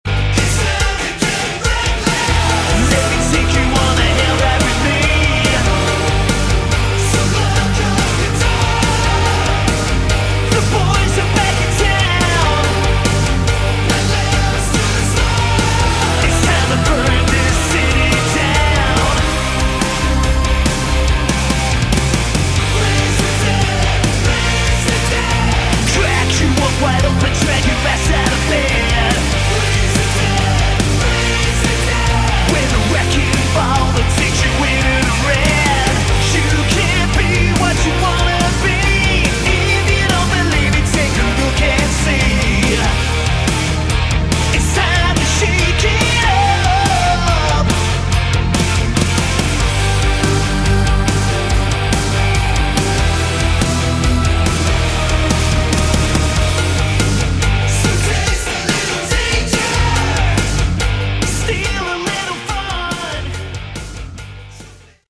Category: Hard Rock
vocals
guitar
keyboards
bass
drums